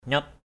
/ɲɔt/ (t.) nhói, nhặt = qui donne une sensation cuisante, qui urge. mahu nyaotmh~% _V)T khát nhặt = assoiffé; lapa nyaot lp% _V)T đói nhặt = affamé; ndok nyaot _QK _V)K đòi nhặt...